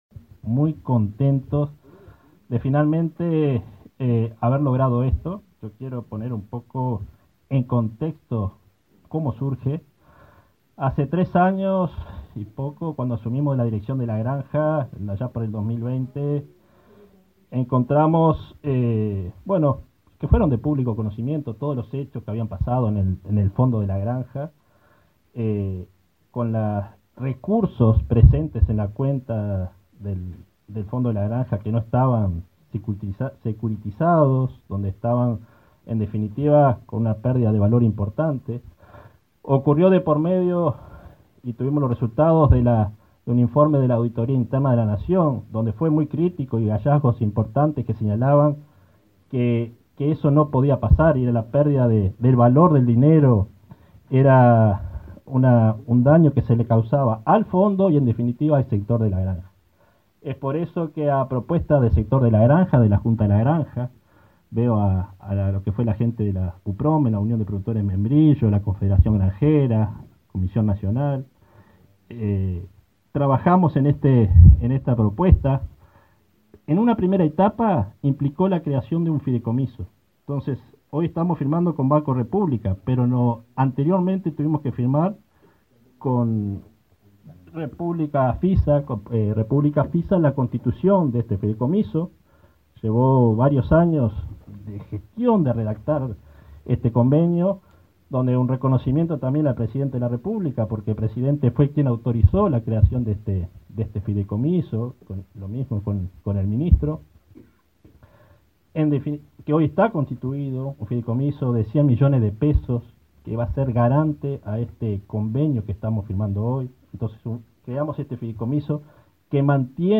Palabra de autoridades en convenio entre el Ministerio de Ganadería y el Banco República
Palabra de autoridades en convenio entre el Ministerio de Ganadería y el Banco República 20/09/2023 Compartir Facebook X Copiar enlace WhatsApp LinkedIn El Ministerio de Ganadería y el Banco República firmaron un convenio, este miércoles 20 en Montevideo, para otorgarle a los granjeros el acceso al crédito con ventajas preferenciales. El director general de la Granja, Nicolás Chiesa; el subsecretario de Ganadería, Ignacio Buffa; el titular del BROU, Salvador Ferrer, y el ministro Fernando Mattos, destacaron la importancia del acuerdo para el sector.